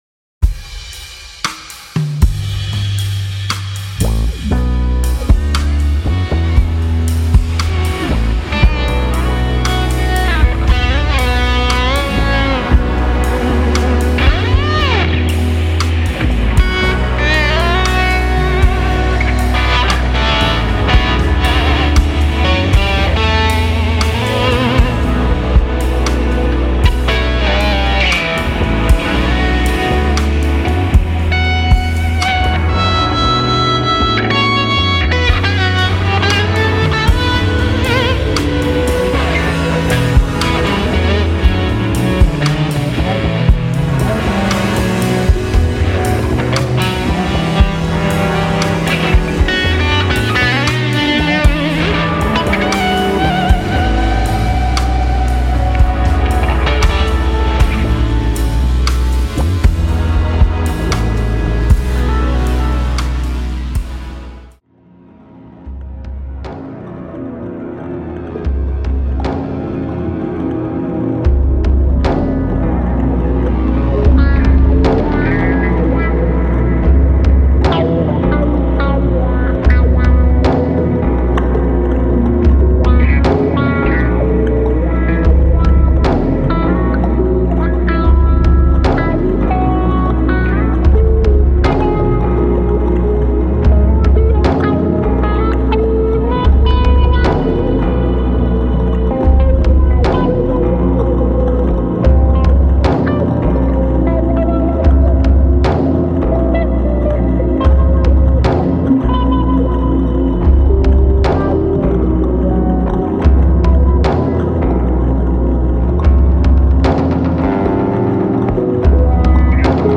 AMBIENT · INDUSTRIAL · EXPERIMENTAL
e-guitars, e-fretless guitar, e-oud, analog guitar synth
beat/samples programming
bass guitar, synths, keys, samples